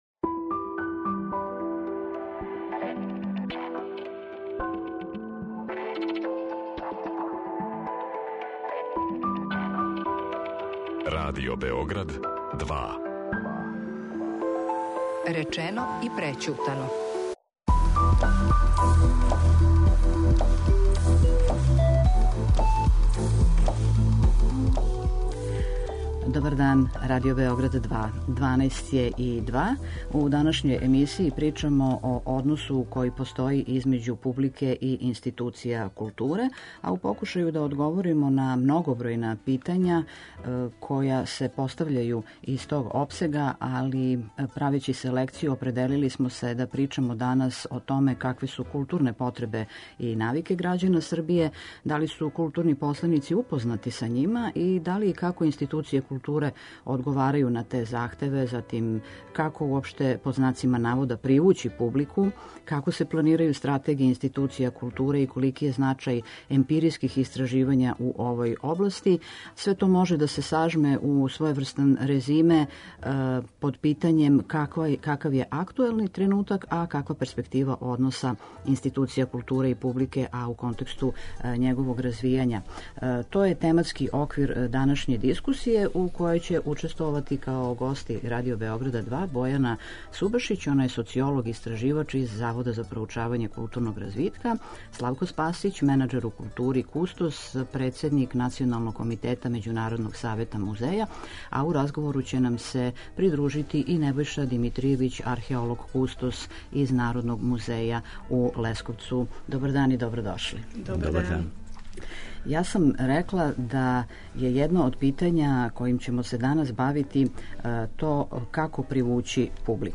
Какве су културне потребе и навике грађана Србије, да ли су културни посленици упознати са њима, да ли и како институције културе одговарају на те захтеве, како привући публику, како се планирају стратегије институција кутуре, колики је значај емпиријских истраживања у овој области, какав је актуелни тренутак, а каква перспектива односа институција културе и публике у контексту њеног развијања, тематски су оквир данашње дискустије у којој ће учествовати: